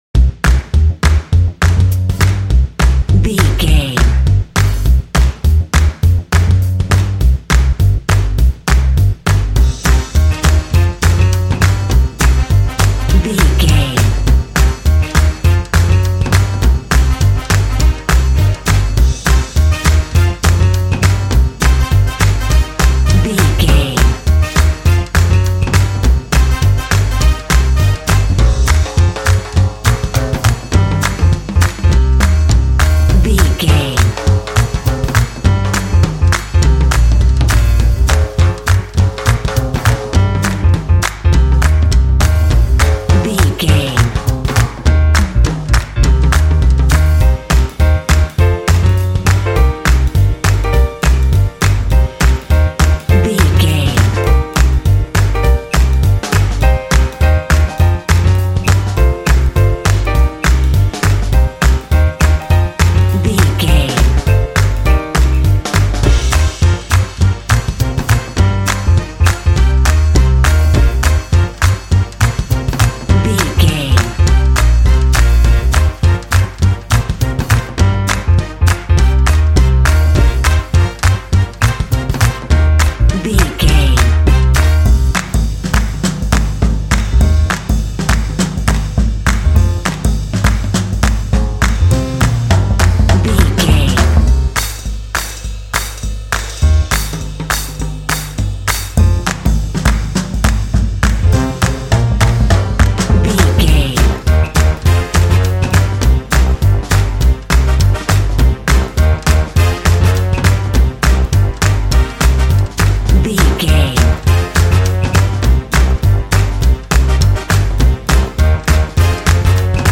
Aeolian/Minor
energetic
bouncy
joyful
double bass
drums
piano
brass
big band